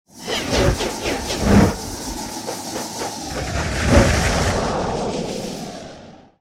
mixed-ghost-voices